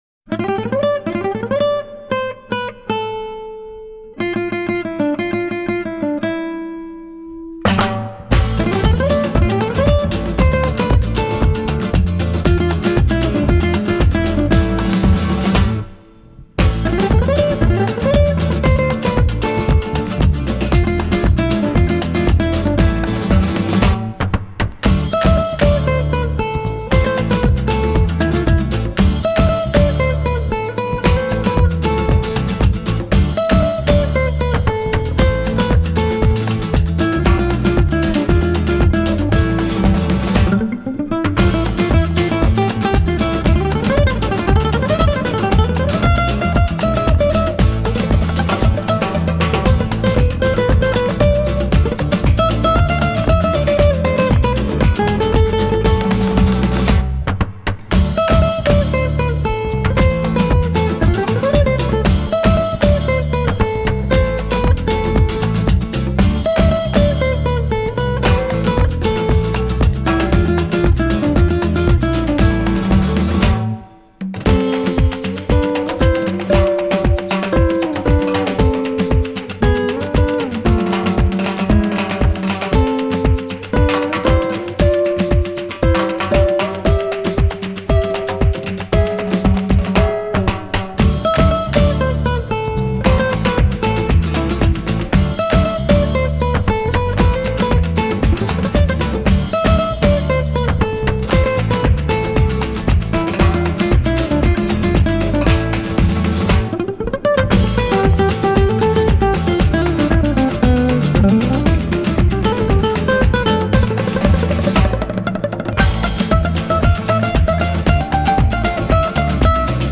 Sensuous rhythms, blinding skill, and explosive guitars!
these are the finest artists of new flamenco